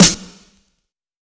Dilla Snare 07.wav